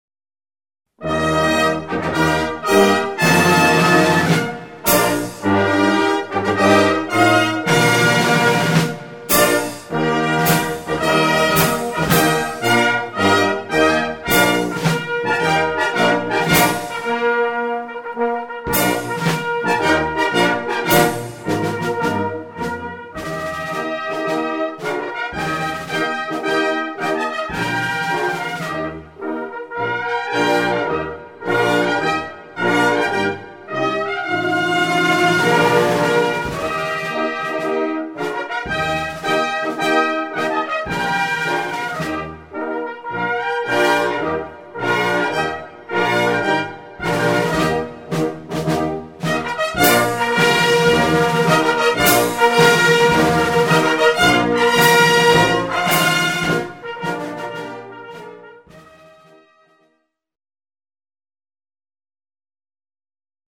Répertoire pour Harmonie/fanfare